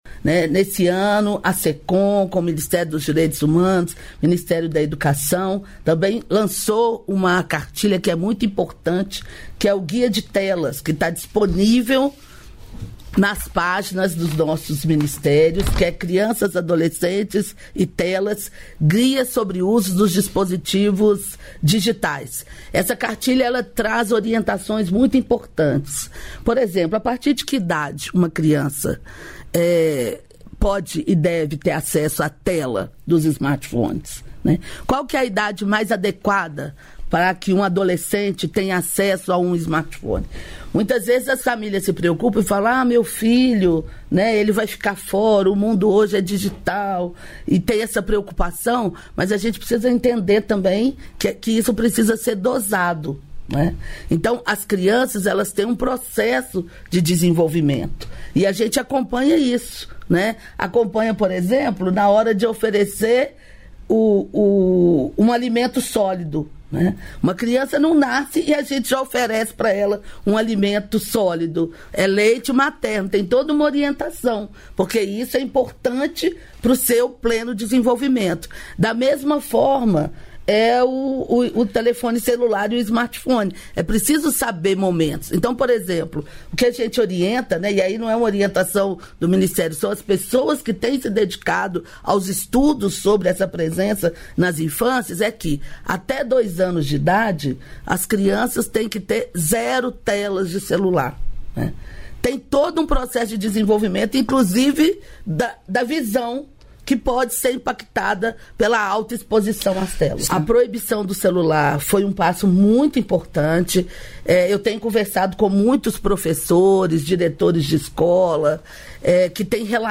Trecho da participação da ministra dos Direitos Humanos e da Cidadania, Macaé Evaristo, no programa "Bom Dia, Ministra" desta quinta-feira (15), nos estúdios da EBC em Brasília (DF).